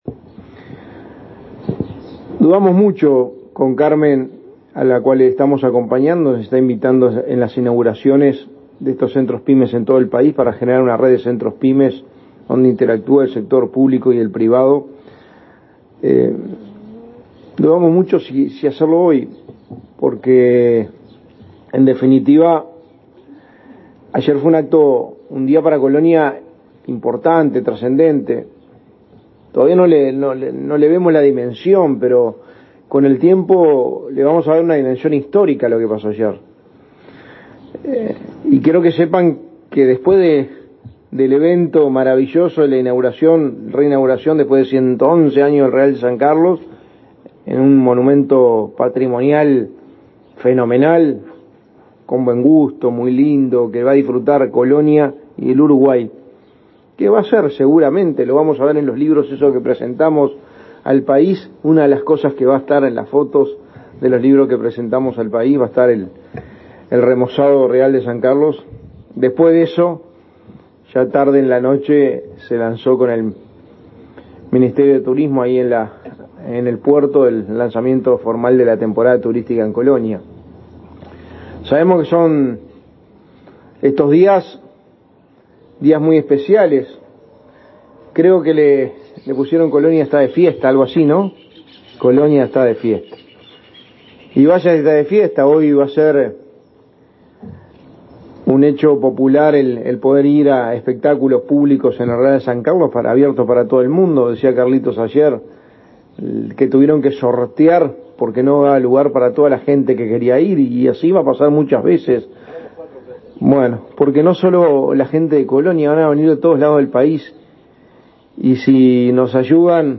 Palabras del secretario de Presidencia, Álvaro Delgado
El secretario de Presidencia, Álvaro Delgado, participó este viernes 10, de la inauguración de un centro Pyme en Colonia.